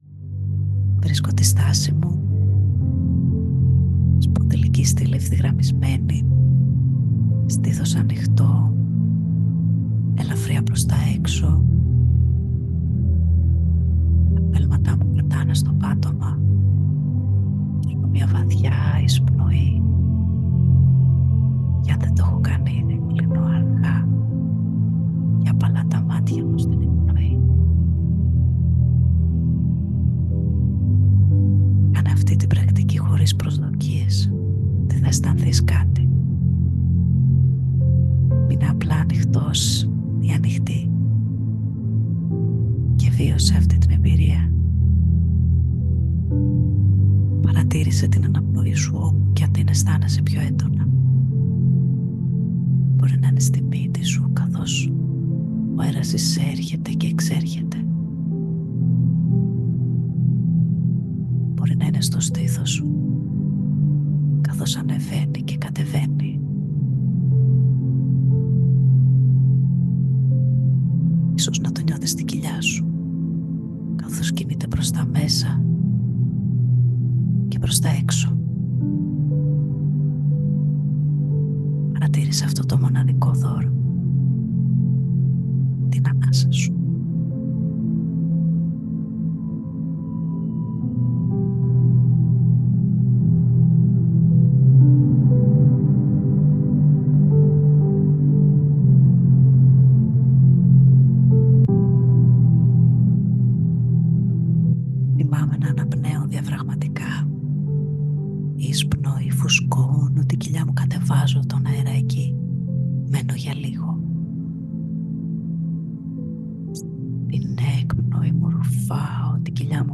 Διαλογισμός Σύνδεσης με την Καρδιά (13 λεπτά)